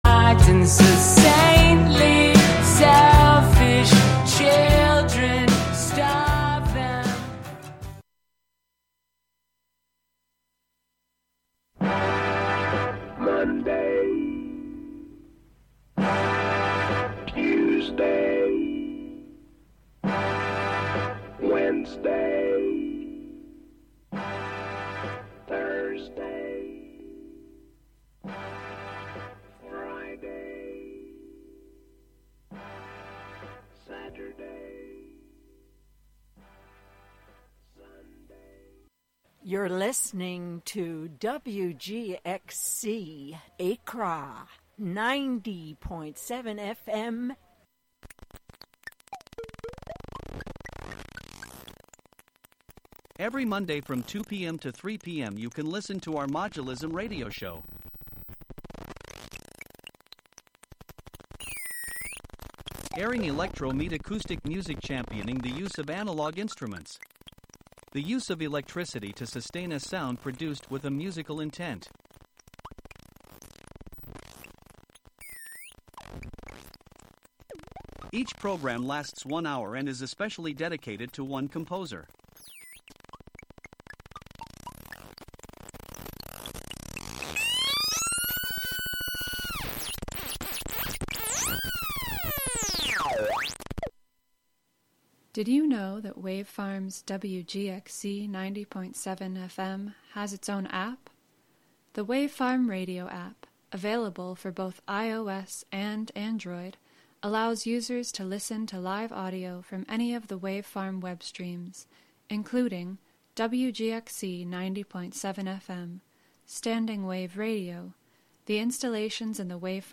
While her segment was recorded in advance, everything else in this hour is LIVE and uncensored.